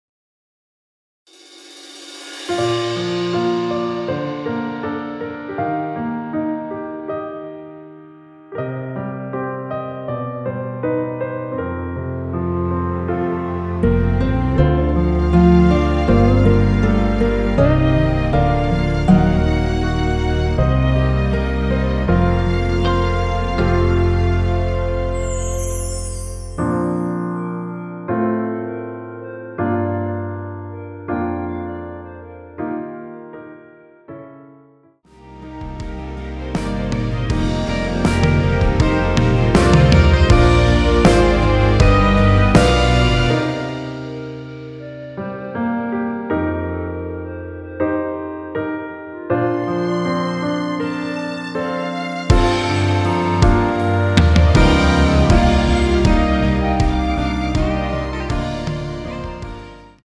원키에서(-3)내린 멜로디 포함된 (1절+후렴) MR입니다.
Bb
앞부분30초, 뒷부분30초씩 편집해서 올려 드리고 있습니다.